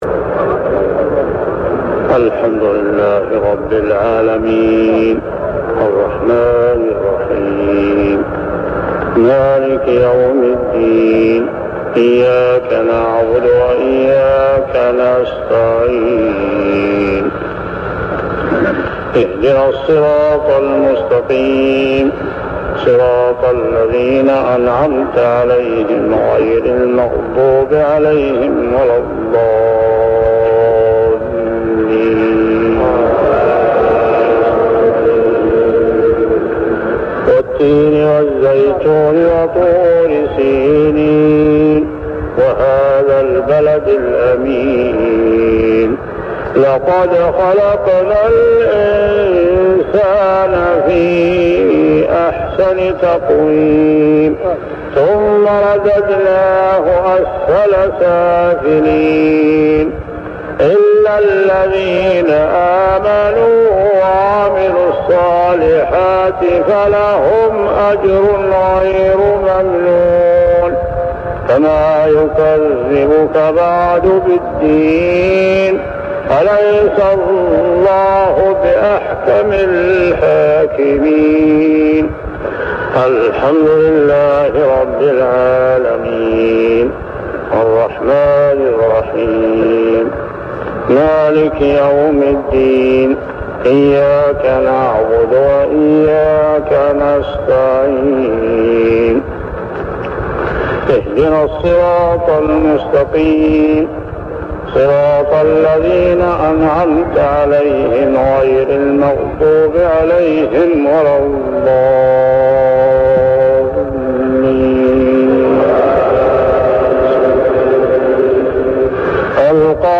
صلاة المغرب عام 1399هـ سورتي التين و القارعة كاملة | maghrib prayer Surah At-Tin and Al-Qari'ah > 1399 🕋 > الفروض - تلاوات الحرمين